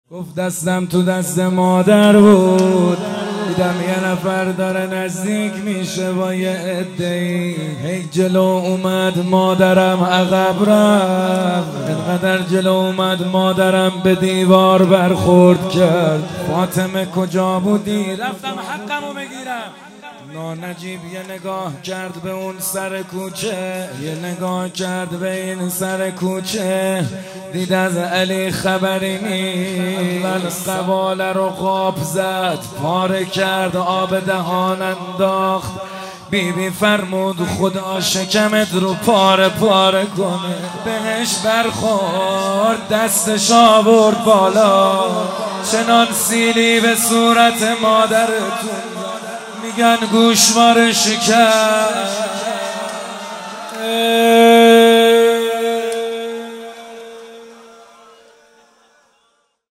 دستم تو دست مادر محمدحسین حدادیان | ایام فاطمیه 1441| تهیه شده توسط خانه هنرپلان 3